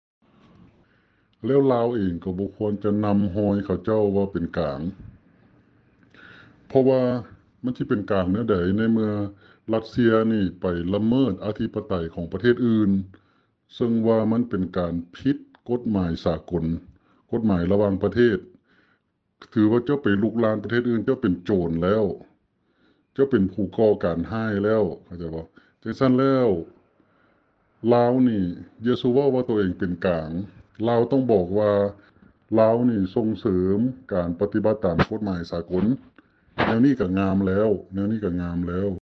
ສຽງພະນັກງານອົງການສາກົນກ່າວກ່ຽວກັບເລື້ອງຣັດເຊຍບຸກລຸກຢູເຄຣນ